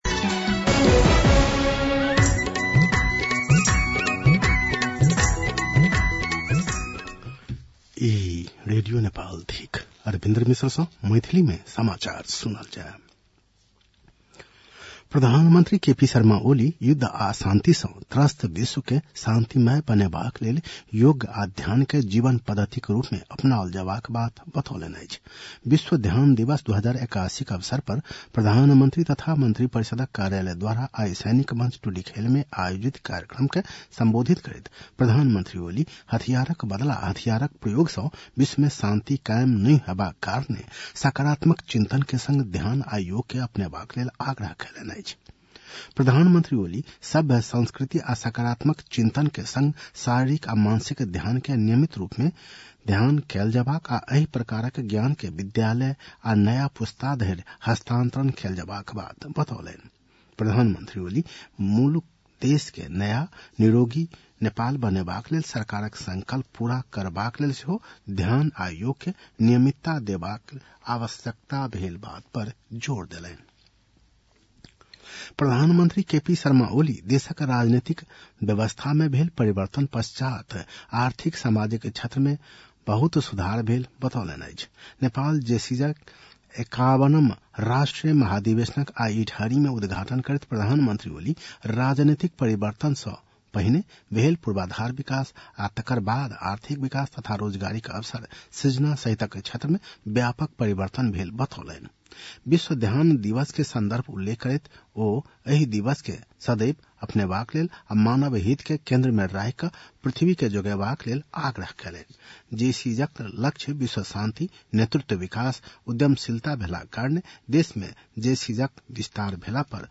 मैथिली भाषामा समाचार : ६ पुष , २०८१